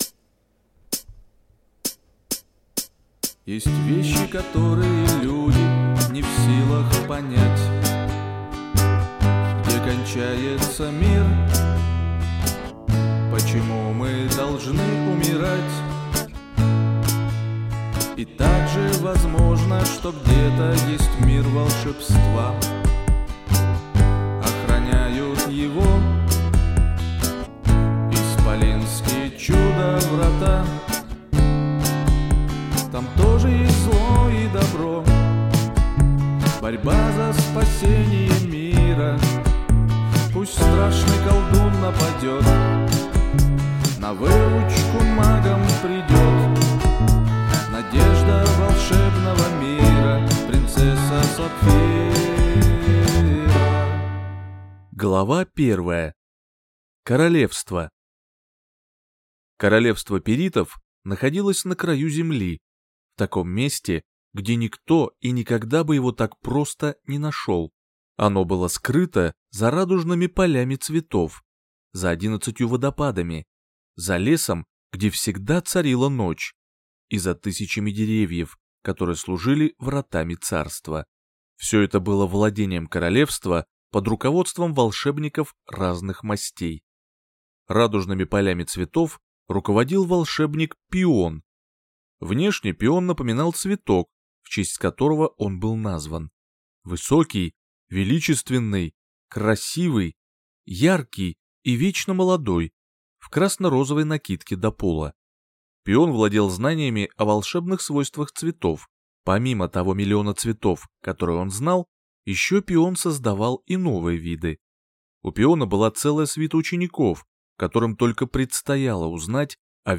Аудиокнига Сапфира | Библиотека аудиокниг